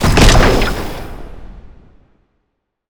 sci-fi_explosion_01.wav